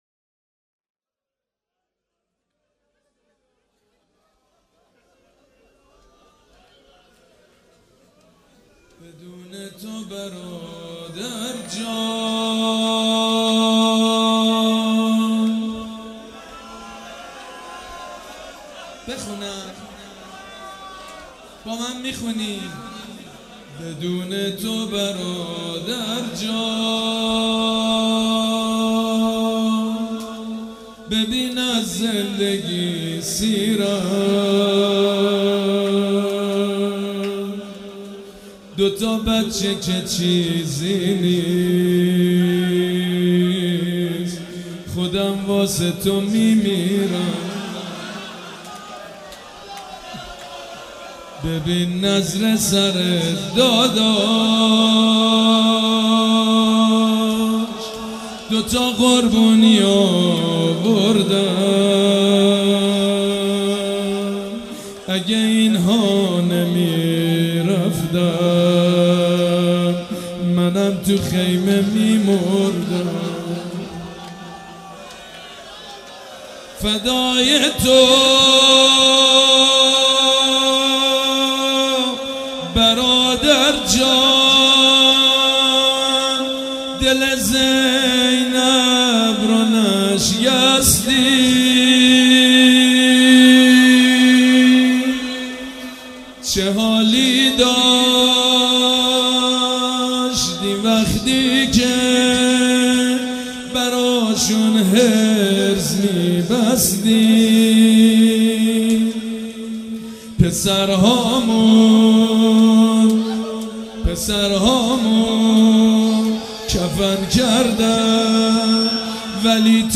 شب چهارم محرم الحرام‌ چهار شنبه ۱4 مهرماه ۱۳۹۵ هيئت ريحانة الحسين(س)
روضه favorite
مداح حاج سید مجید بنی فاطمه